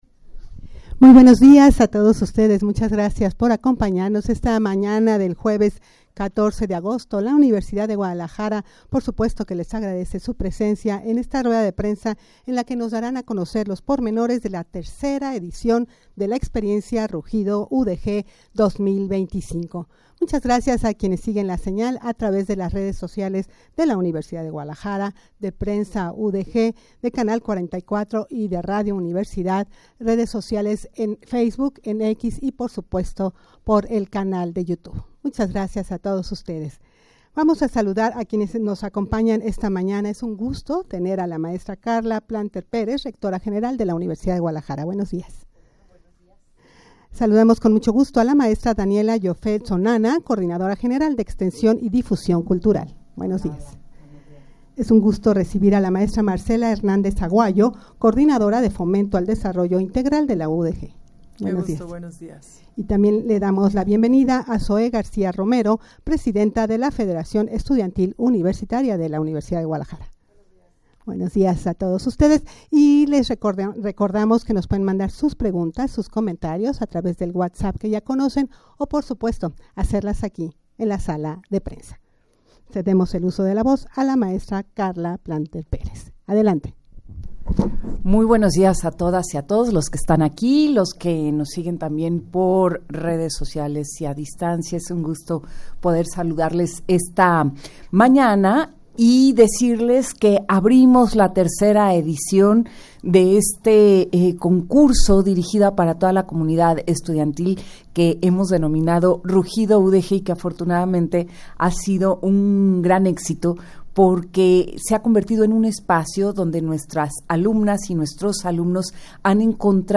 Audio de la Rueda de Prensa
rueda-de-prensa-para-dar-a-conocer-la-tercera-edicion-de-la-experiencia-rugido-udeg-2025.mp3